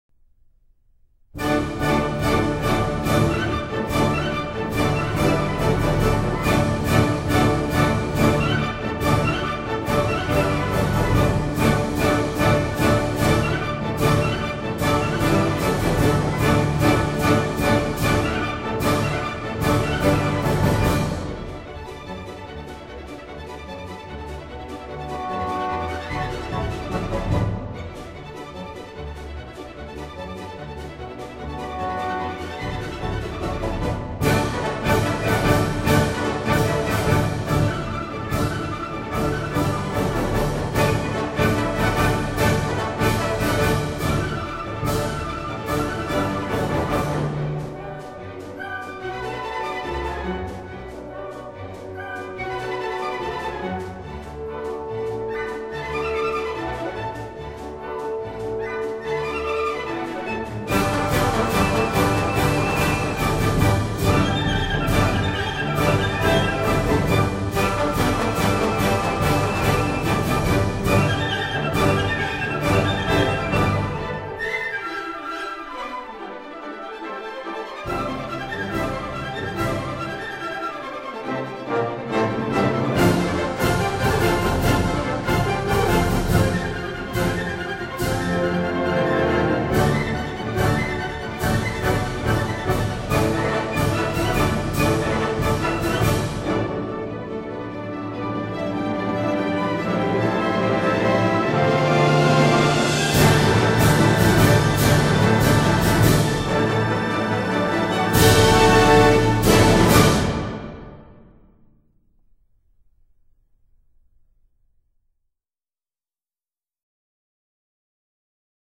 按照大型管弦乐团的器乐编制以及演奏要求改编出这套《音乐会组曲》